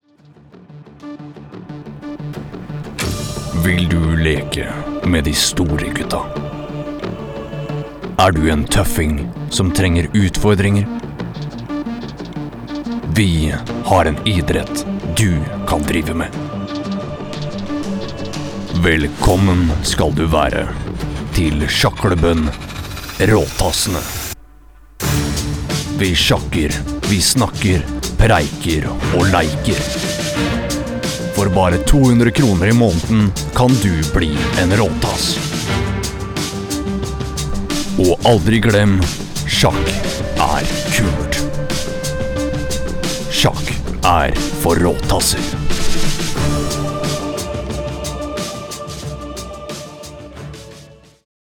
uma voz barítono norueguesa, profunda e acolhedora
Trailers de filmes
Mic: Shure SM7B + Trtion Audio Fethead Filter
BarítonoProfundoBaixo